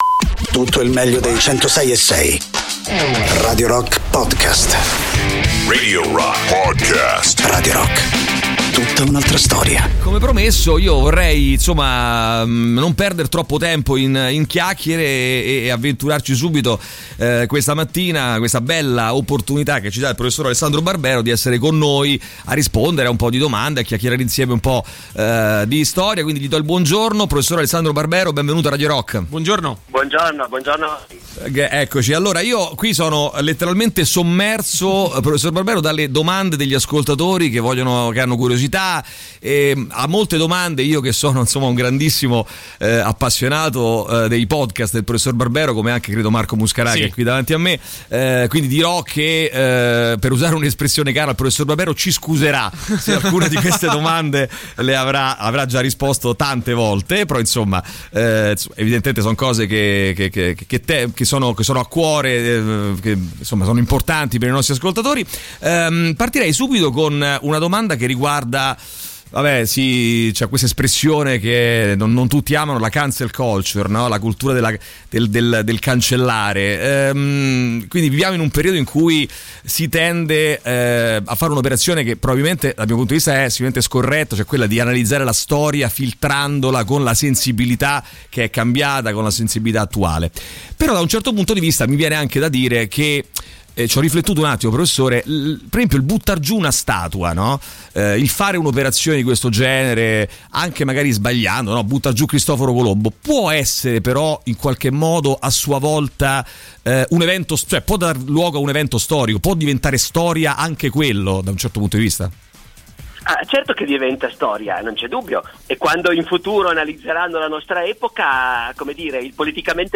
Interviste: Alessandro Barbero (19-10-22)